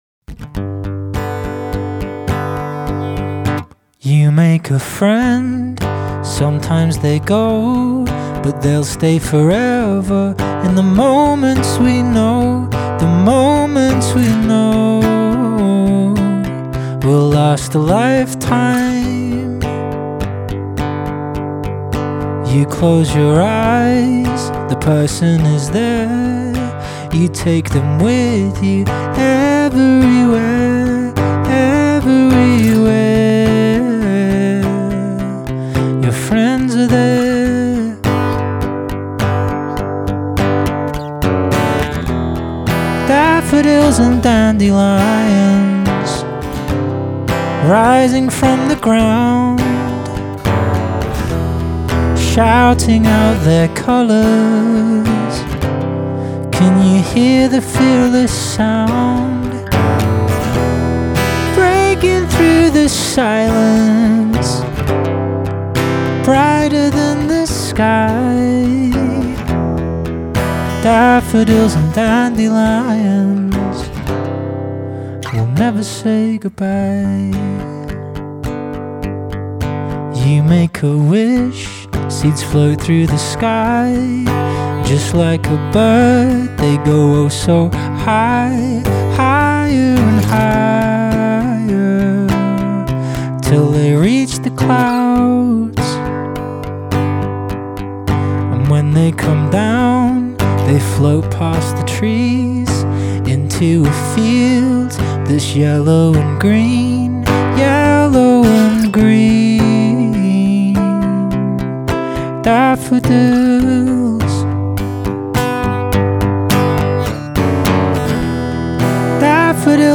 Written by Service children at the North West Wales Armed Forces Festival (November 2025).